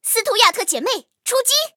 M3斯图亚特出击语音.OGG